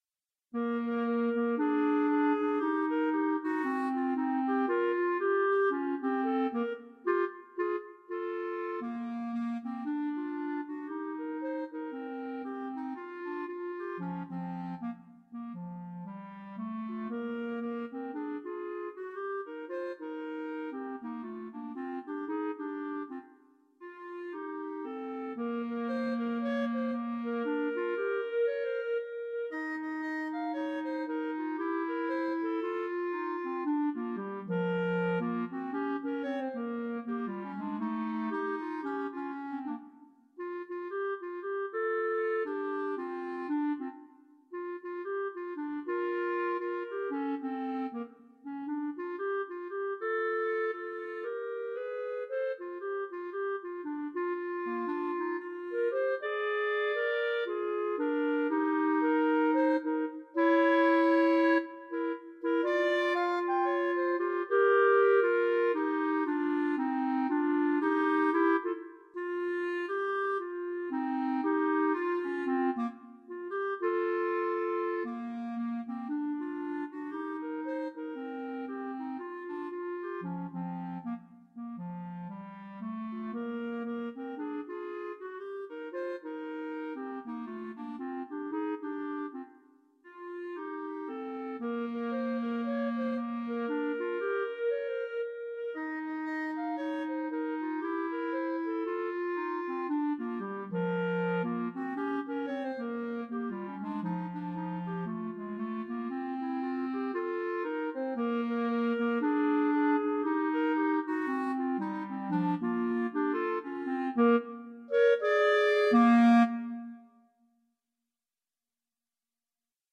An original swing number
Jazz and Blues